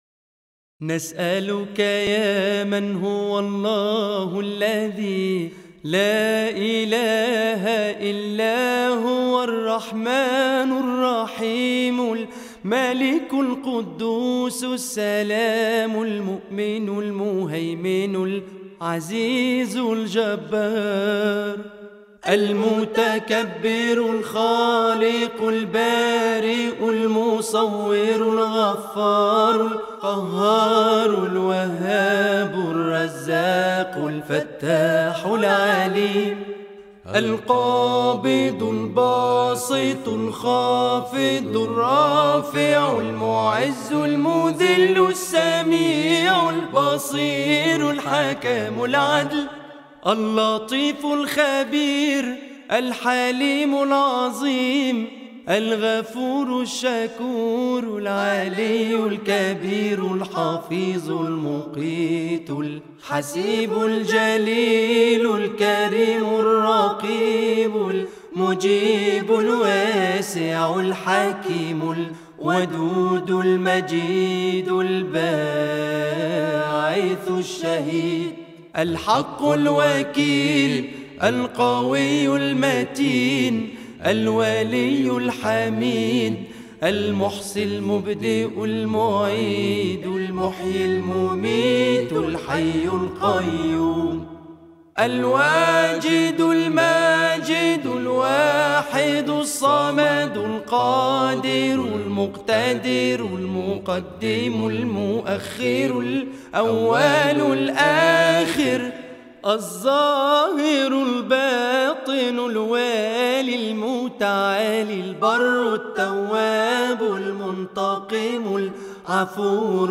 Learn the 99 Beautiful Names of Allah (Asmaa Allah Al-Husna) through an interactive sequence game with audio pronunciation.